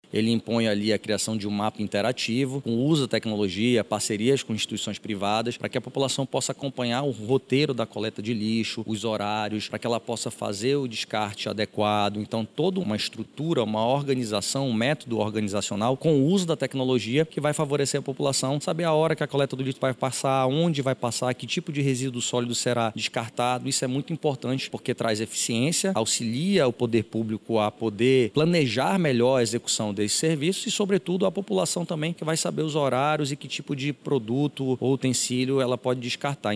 Em relação ao Projeto de Lei que cria o programa “Manaus Limpa”, o autor da proposta, o vereador Rodrigo Sá, do Progressistas, explica como o programa vai funcionar, caso seja aprovado pela Casa Legislativa.